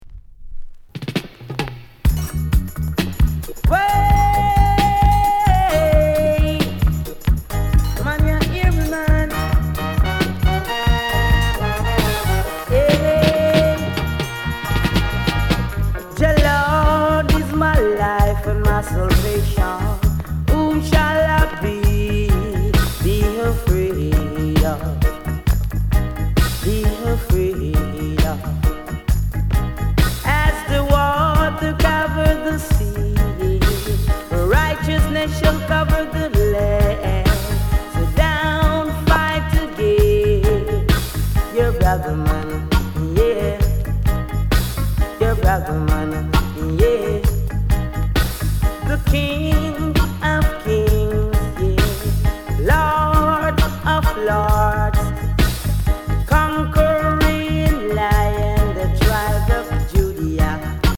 SOUND CONDITION VG(OK)